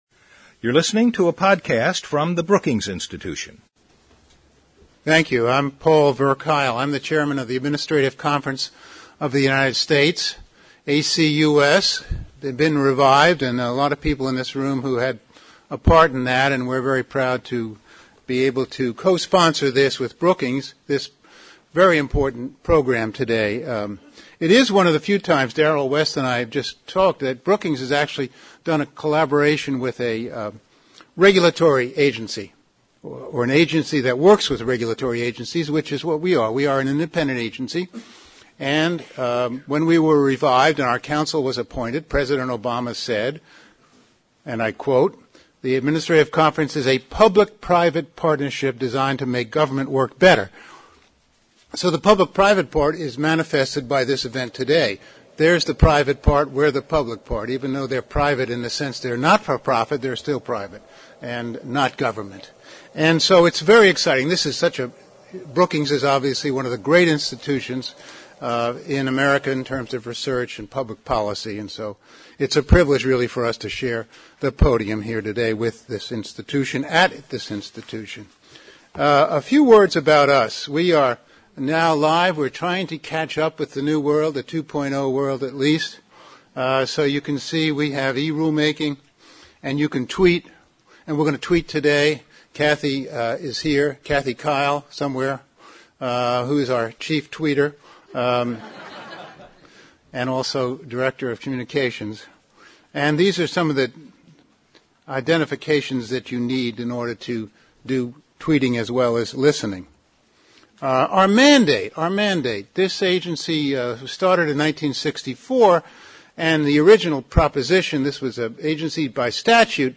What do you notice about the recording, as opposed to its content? On November 30, the Center for Technology Innovation at Brookings and the Administrative Conference of the United States hosted a public forum to explore how new technologies can promote more effective public participation and greater efficiency in the rulemaking process.